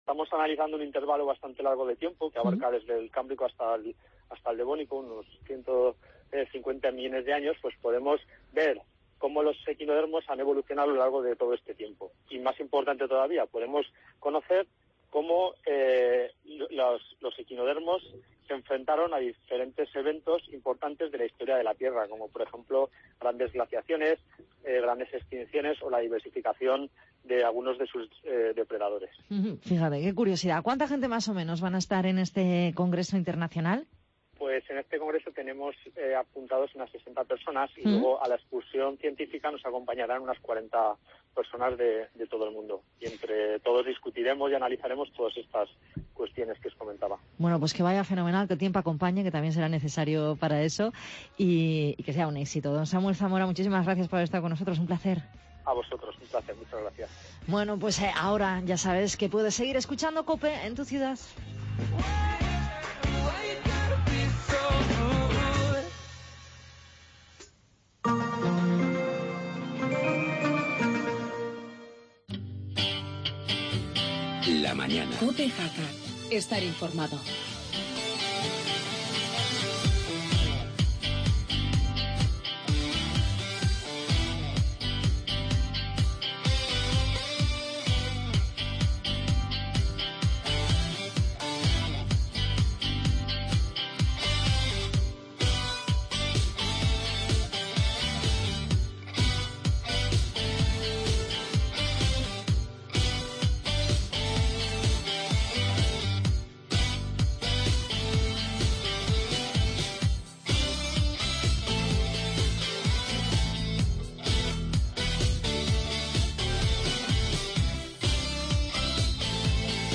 AUDIO: Actualidad tras la constitución de los nuevos ayuntamientos, entrevista al nuevo alcalde de Jaca Juan Manuel Ramón Ipas y debate Jacetano.